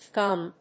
読み方：エフ・ジー・エム